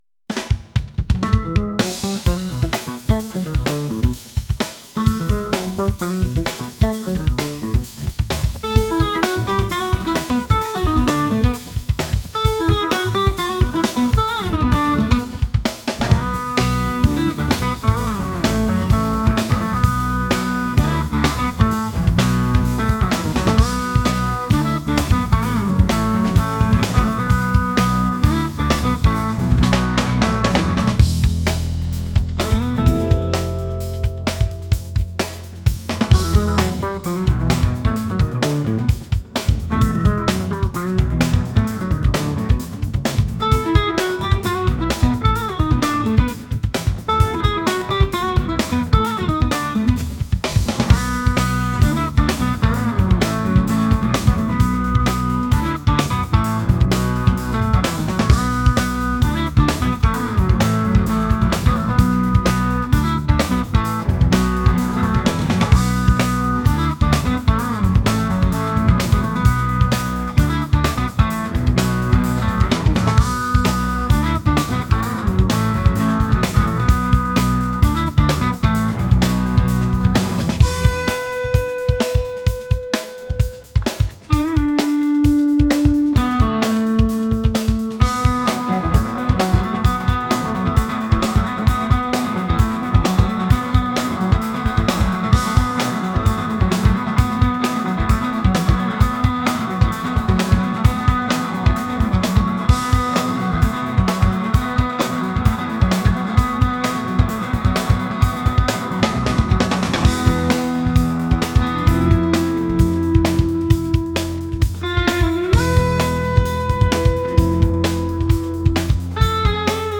funky | fusion | jazz